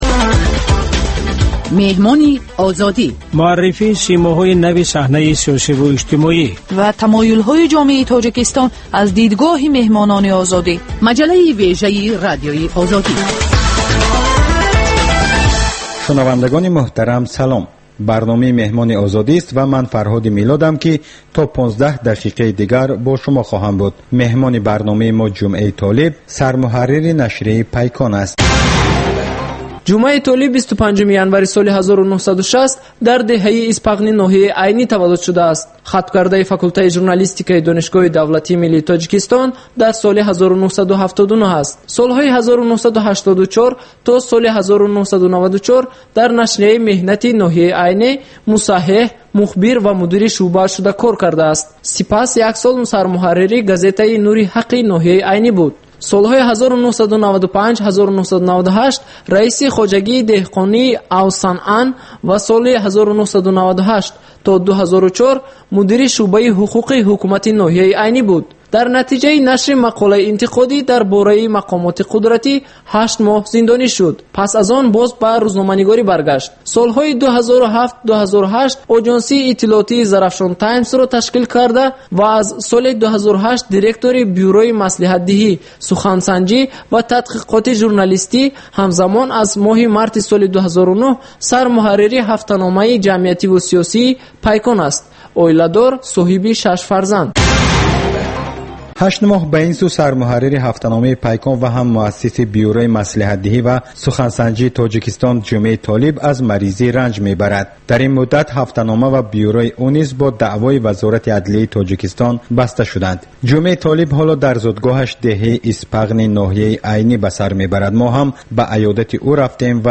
Гуфтугӯи ошкоро бо шахсони саршинос ва мӯътабари Тоҷикистон.